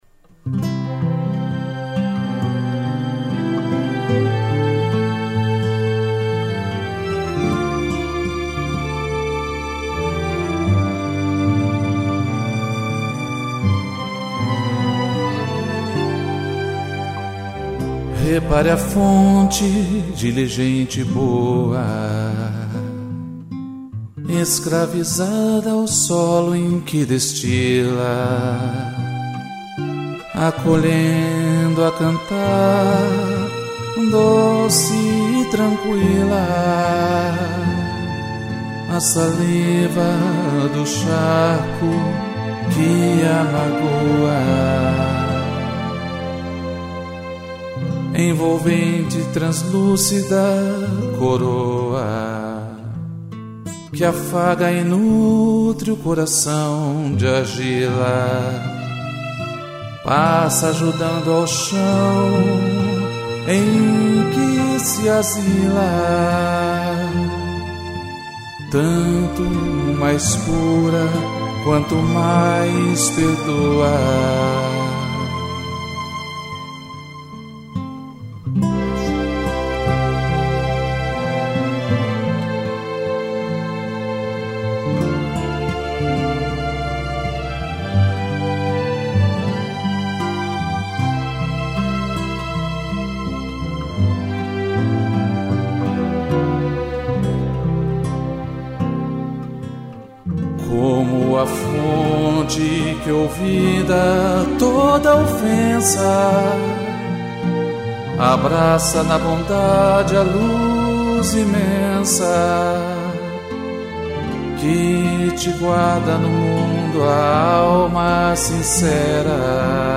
voz
piano e cello